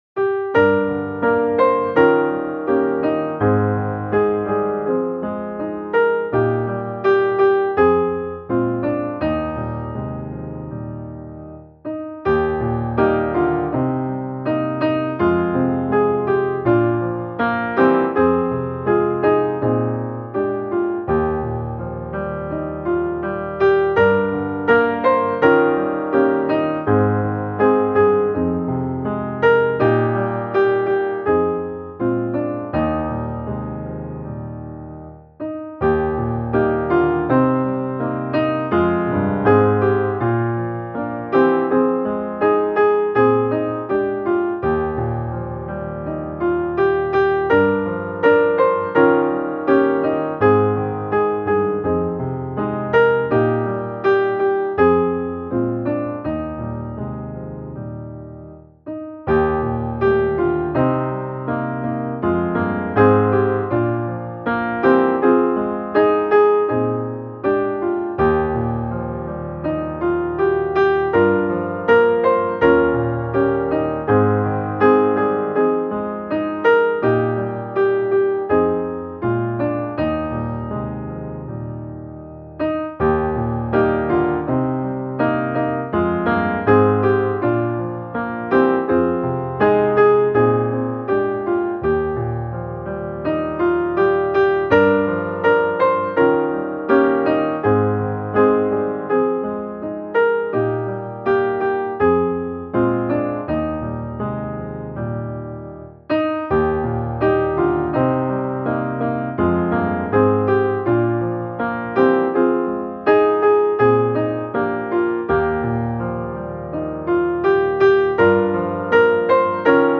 musikbakgrund